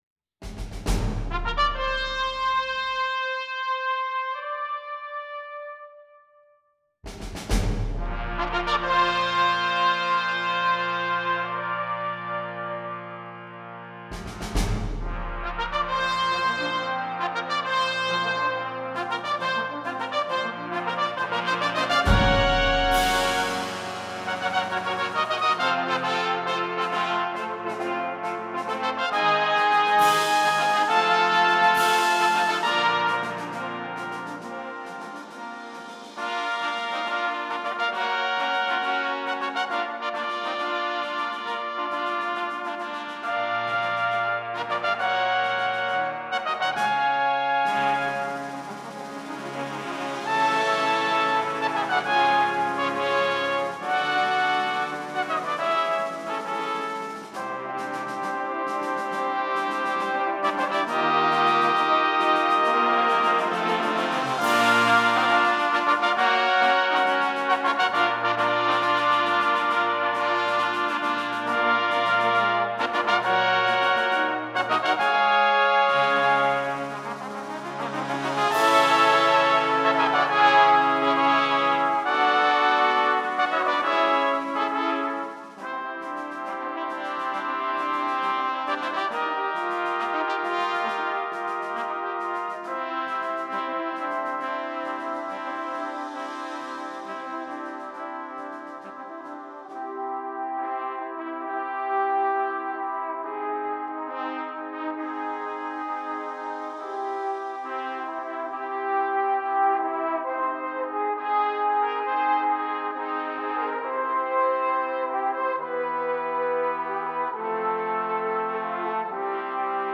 Number of Trumpets: 3
2 A set of four cute and easy works for the younger players.
easy trios